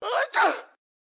Sneeze 4
Sneeze 4 is a free sfx sound effect available for download in MP3 format.
Sneeze 4.mp3